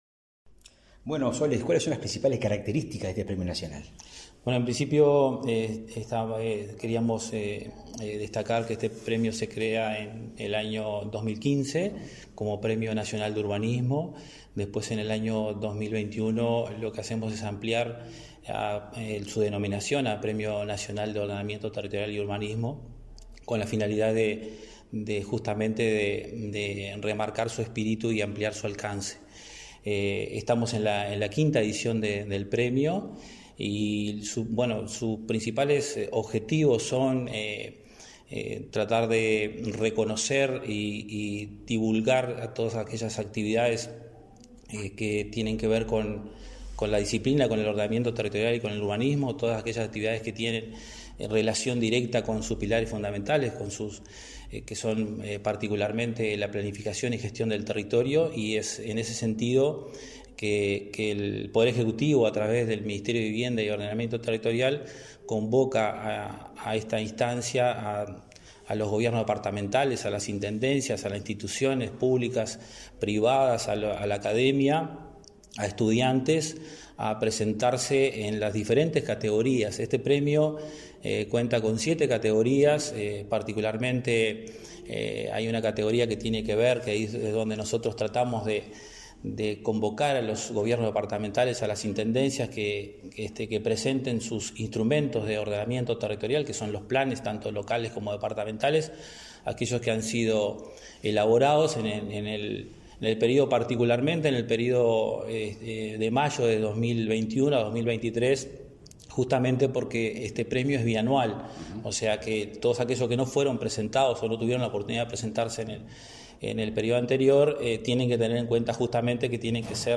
Entrevista al director nacional de Ordenamiento Territorial, Norbertino Suárez